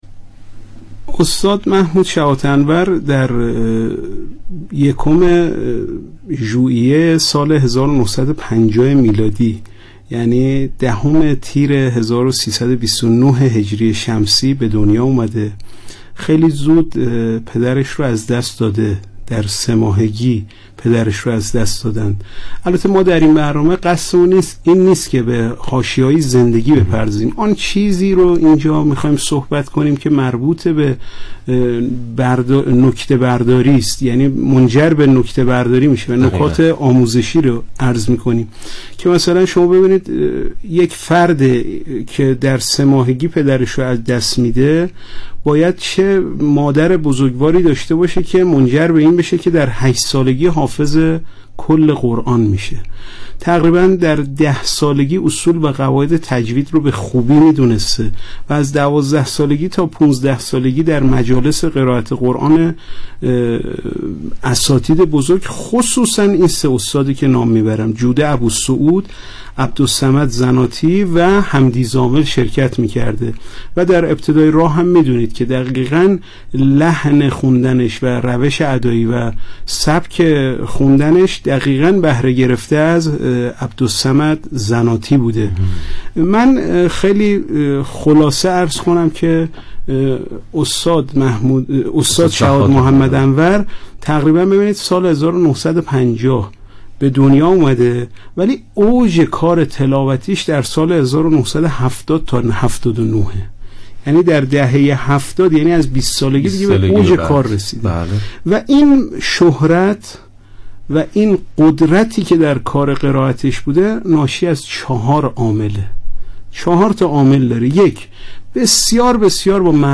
گروه فعالیت‌های قرآنی ــ بعضی از اساتید، ویژگی‌های موسیقیایی قرائت شحات محمد انور را به ردیف و موسیقی ایرانی خیلی نزدیک دانسته‌اند و این موضوع را عامل جذبه تلاوت ایشان برای ایرانی‌ها می‌دانند.